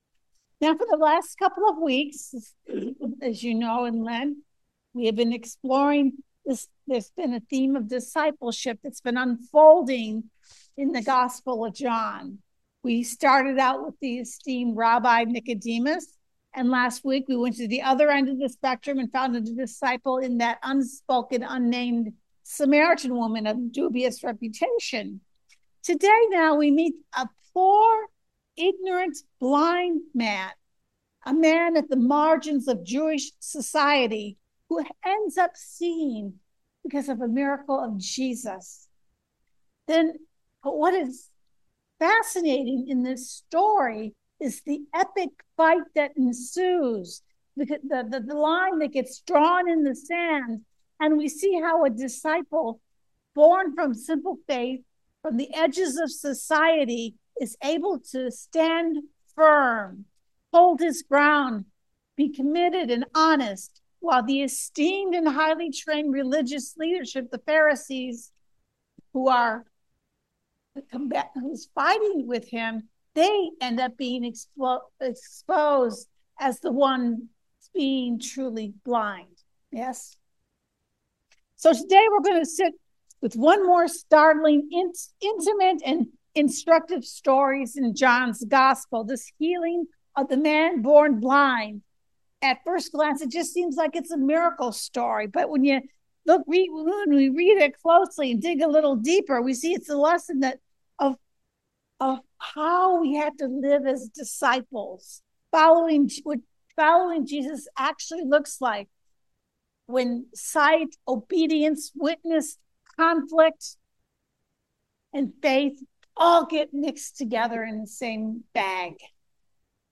Ephesians 5:8-14 Service Type: Sunday Morning Baptism is sometimes called enlightenment.